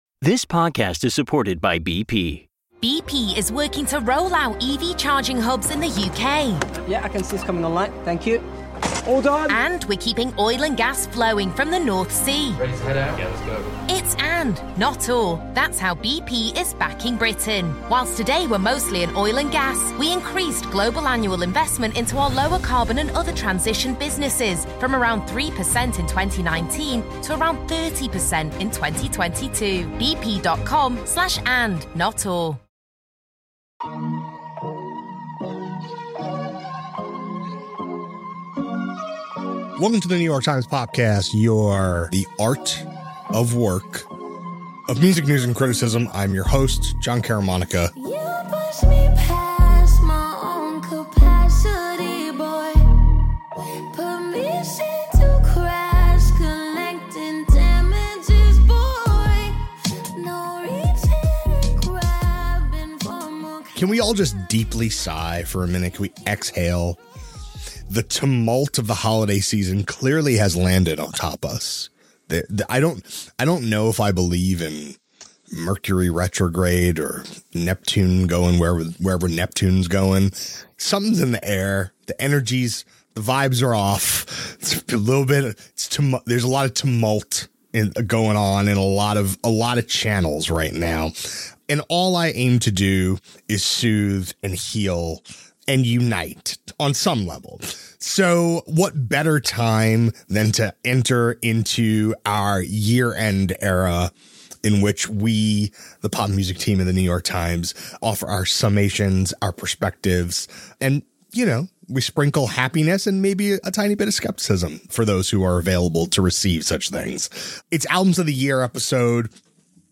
Our critics debate their year-end lists (and agree on SZA, Olivia Rodrigo and 100 gecs!) in a wrap-up of the year in LPs.